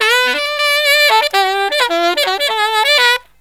63SAXMD 10-R.wav